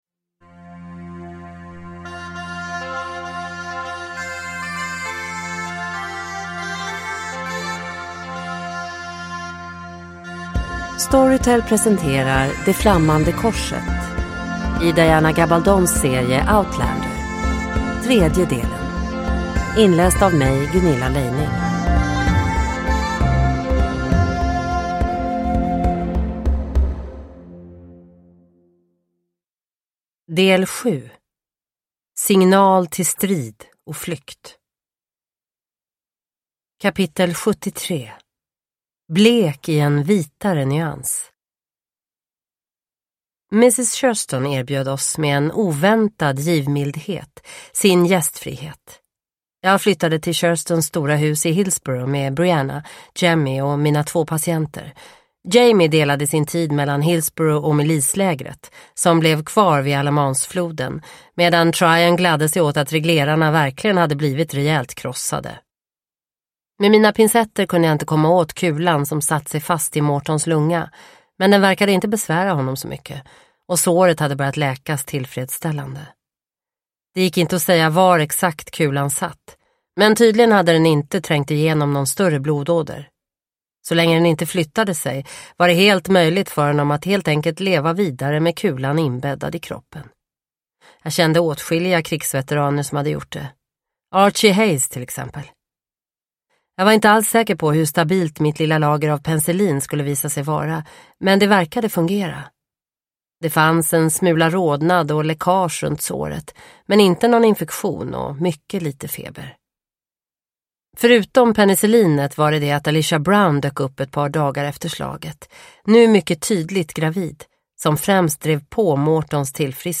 Det flammande korset - del 3 – Ljudbok – Laddas ner